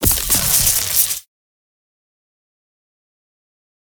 chain-lightning-secondary-strike-5ft-00.ogg